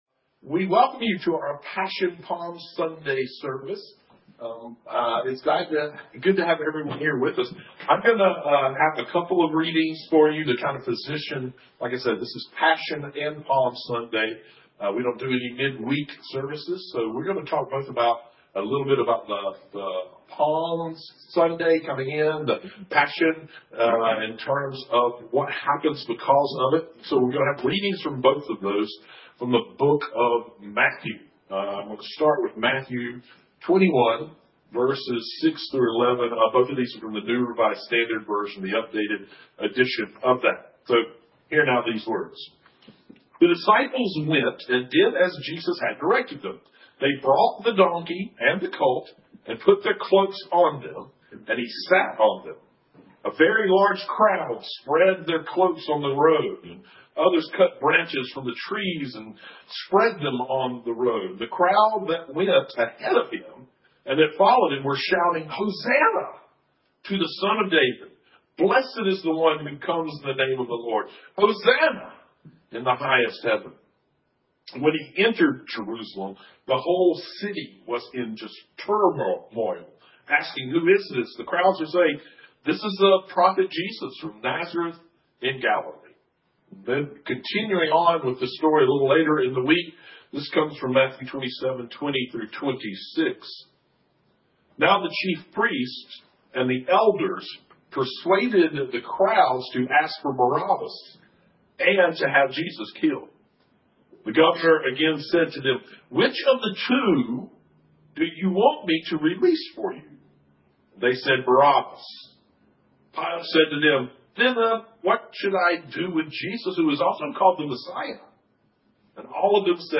Guest Musician